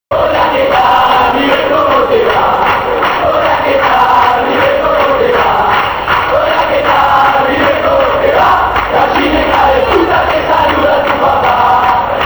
Zde si můžete stáhnout 4 chorály Bocy (3 z nich natočeny přímo v kotli La Doce u kapely):
Chorál 4 (0,1 MB)